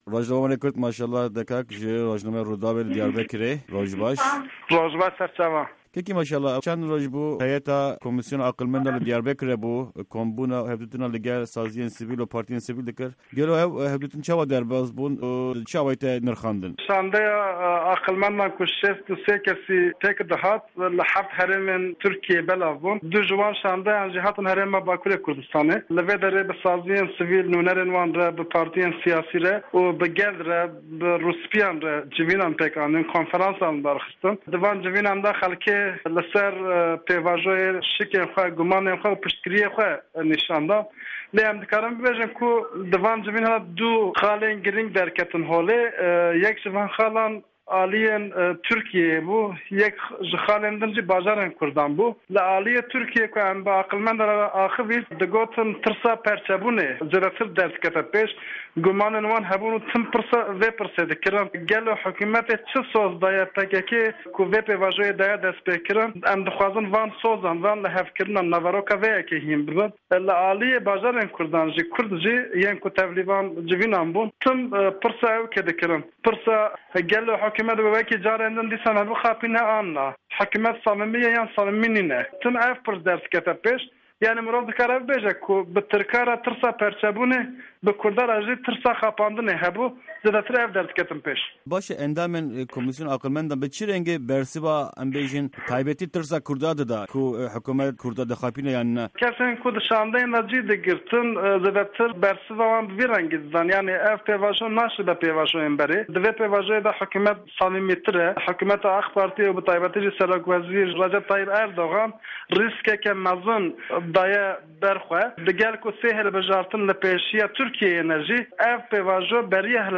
Di hevpeyvîna Pişka Kurdî ya Dengê Amerîka de